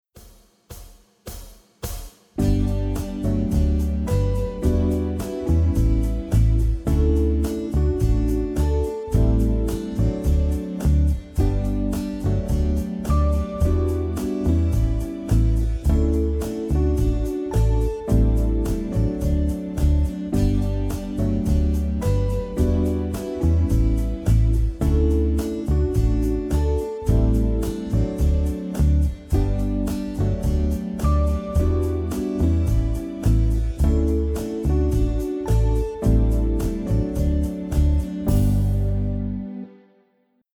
Akkordprogression med modulation og gehørsimprovisation:
Lyt efter bassen, der ofte spiller grundtonen.
Modulation: En kvart op eller en kvart ned
C instrument (demo)